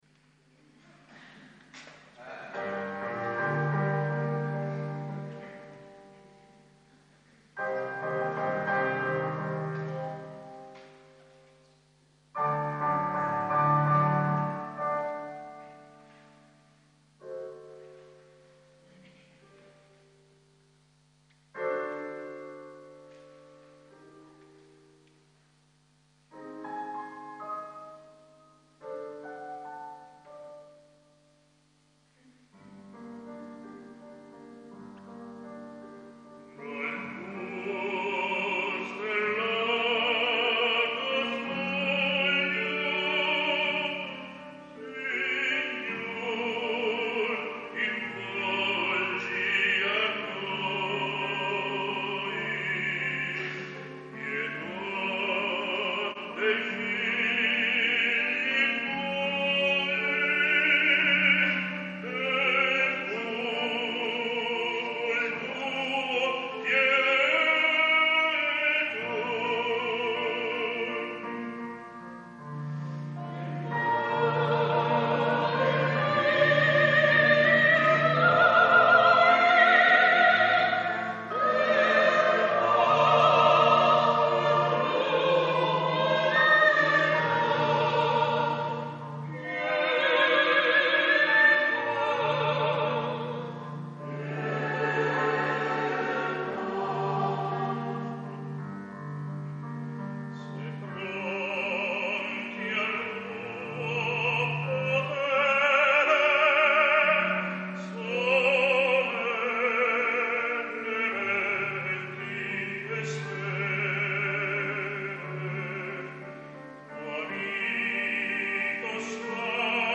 Coro Polifonico Beato Jacopo da Varagine - Varazze